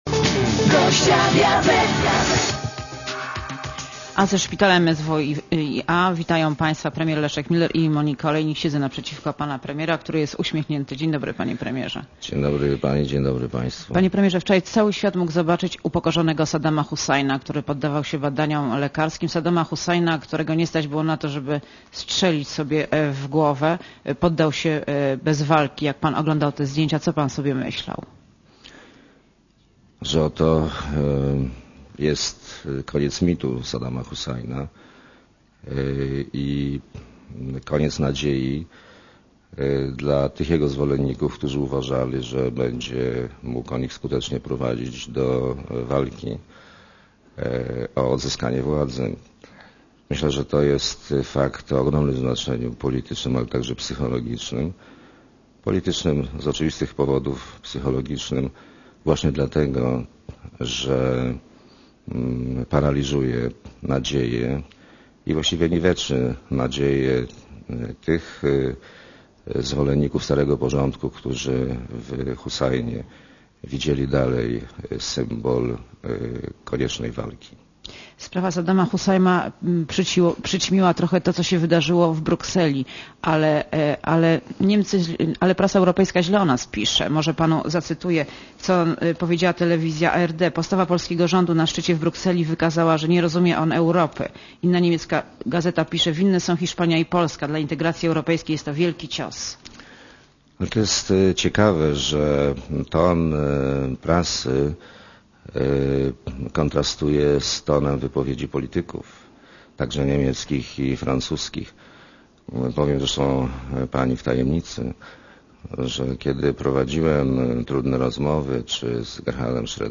A ze szpitala MSWiA witają Państwa premier Leszek Miller i Monika Olejnik.